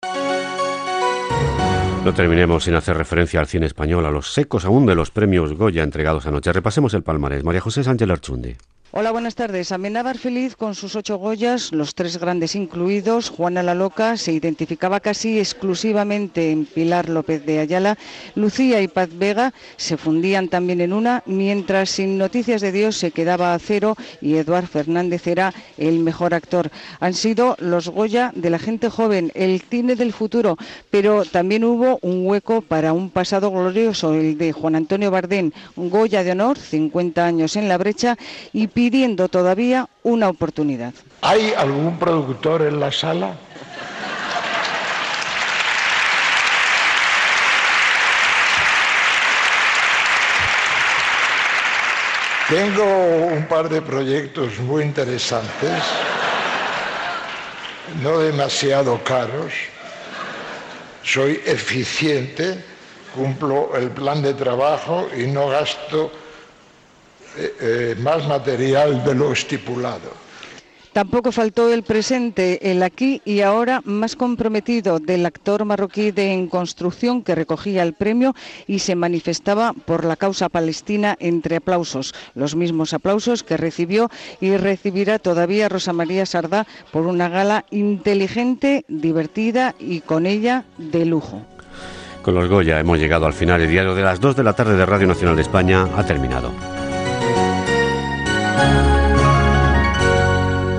Informació de la gala de lliurament dels Premios Goya de l'any 2002, amb un fragment del discurs de Juan Antonio Bardem, Goya de Honor
Informatiu